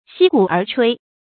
析骨而炊 注音： ㄒㄧ ㄍㄨˇ ㄦˊ ㄔㄨㄟ 讀音讀法： 意思解釋： 同「析骸以爨」。